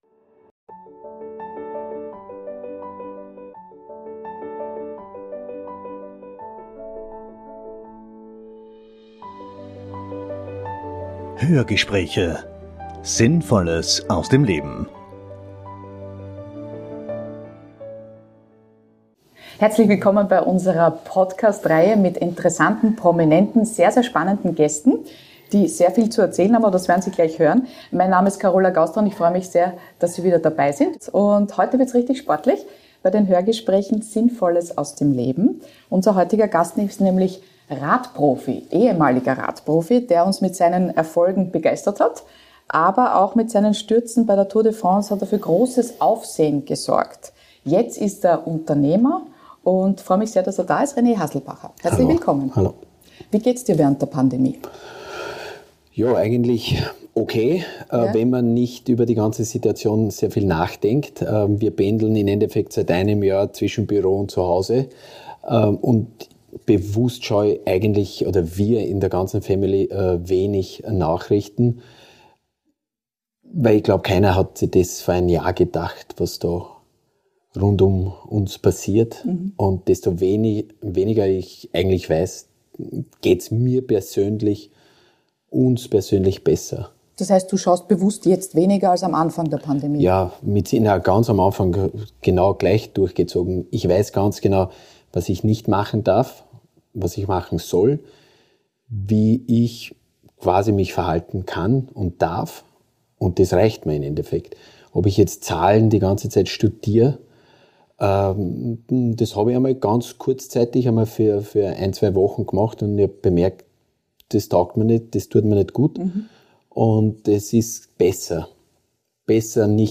Wir haben René Haselbacher deshalb zum Interview gebeten,...